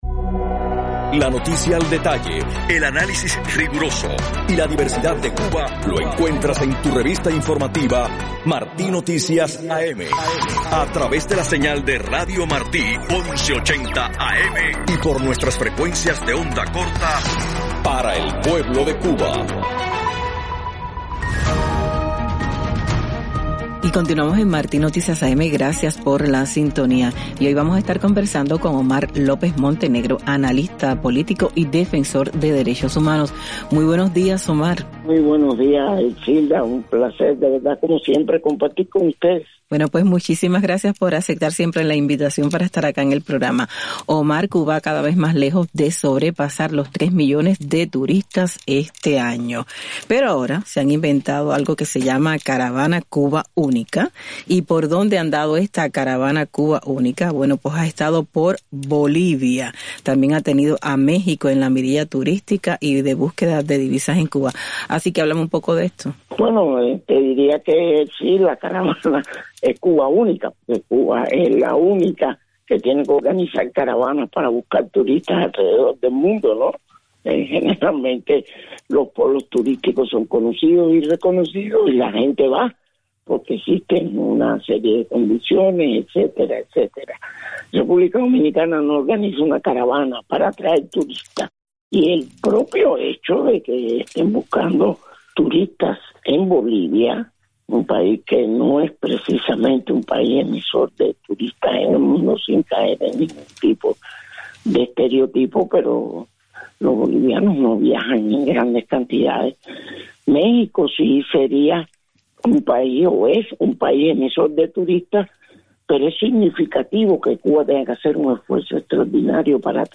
Declaraciones del analista político